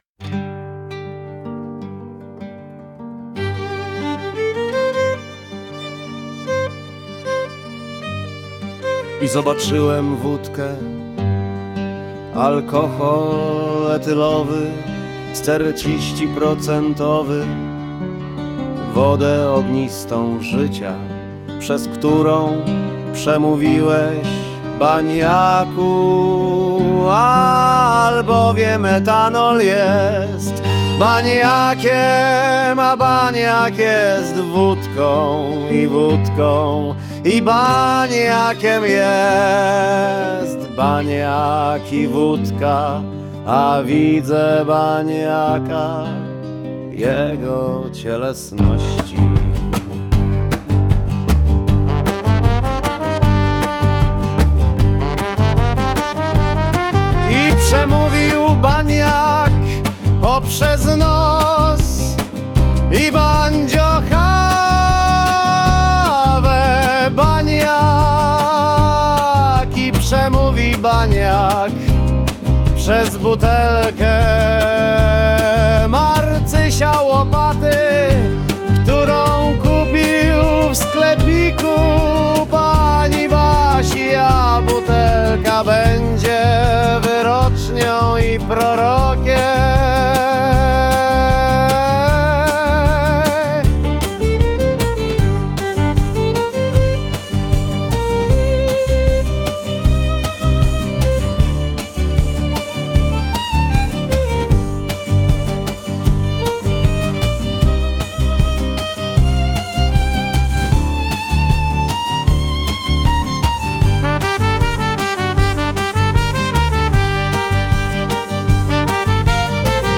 Banjacka_piosenka_folkowa.mp3